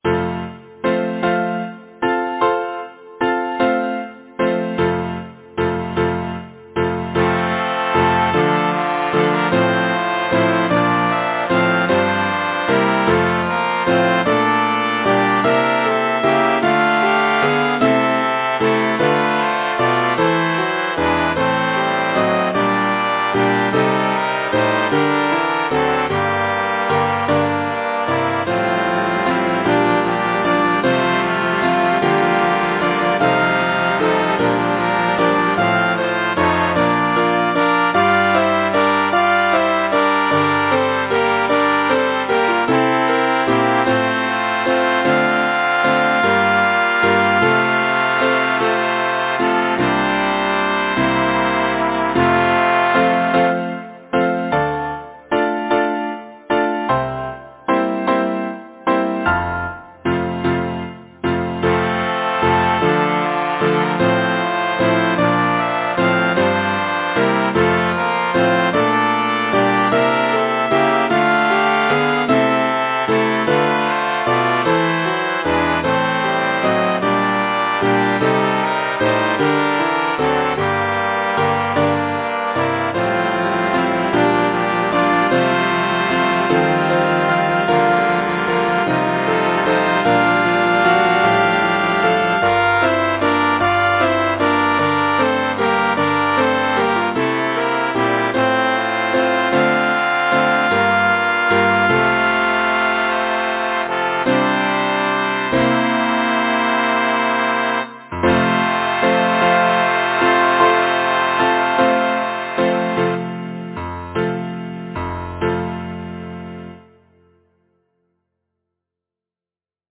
Title: Orpheus and his Lute Composer: Edward German Lyricist: William Shakespeare Number of voices: 4,8vv Voicings: SATB or SSAATTBB Genre: Secular, Partsong
Language: English Instruments: piano